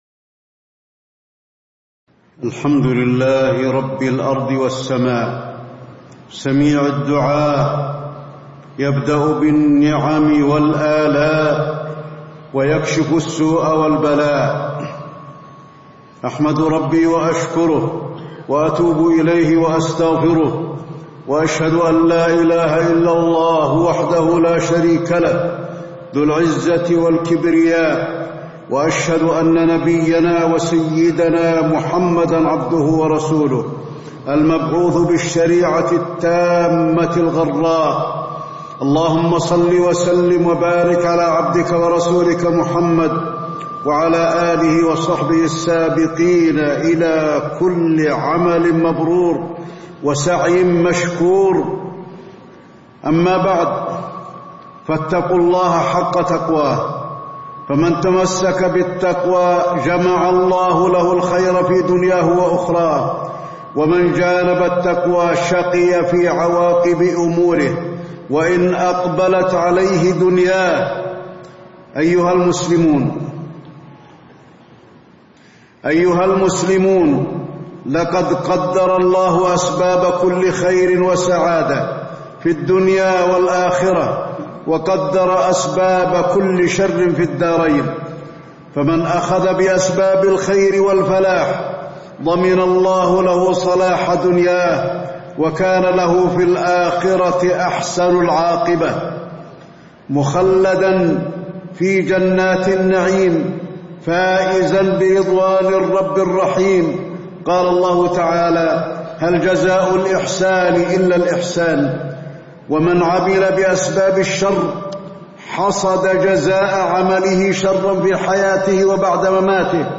تاريخ النشر ٩ جمادى الآخرة ١٤٣٧ هـ المكان: المسجد النبوي الشيخ: فضيلة الشيخ د. علي بن عبدالرحمن الحذيفي فضيلة الشيخ د. علي بن عبدالرحمن الحذيفي فضل الدعاء وأحكامه